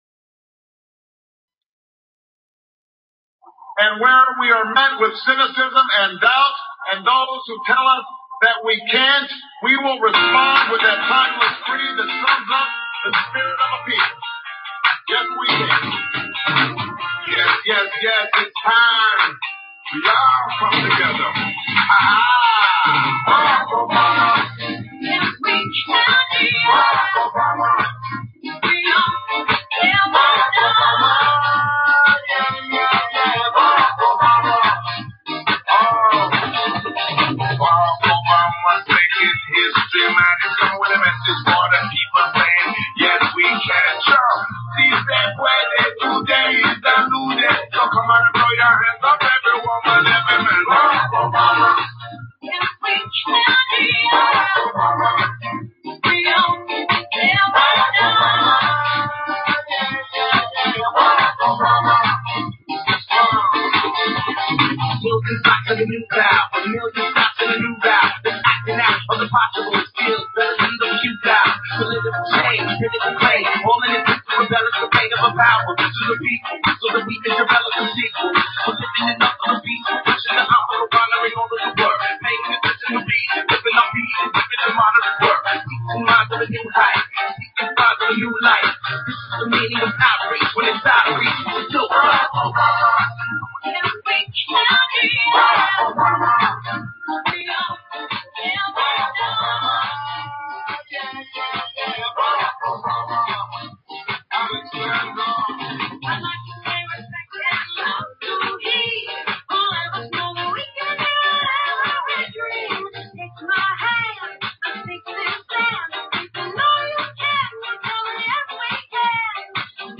Talk Show Episode, Audio Podcast, Galactic_Healing and Courtesy of BBS Radio on , show guests , about , categorized as